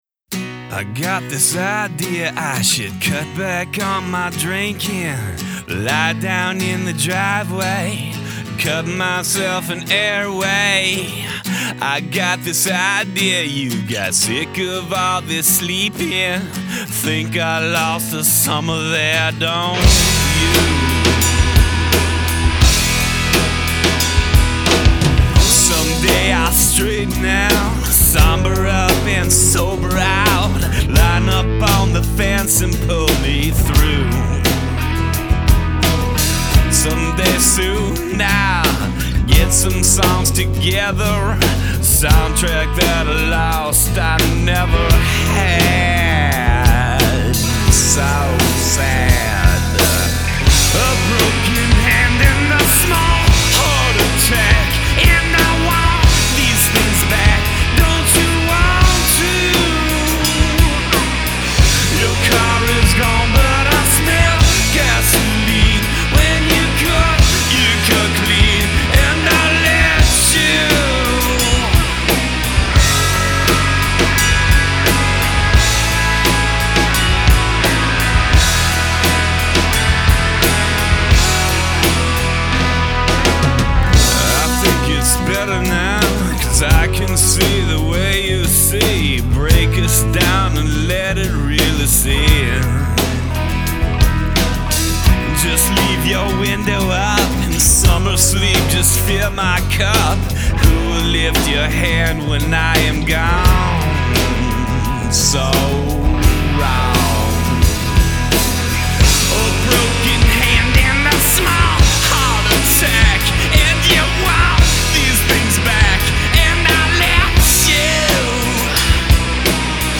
gtr, drums
bass